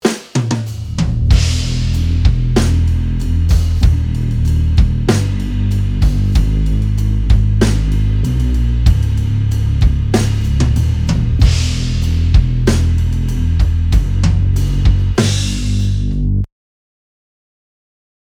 Drums Pop Recording Tracks Session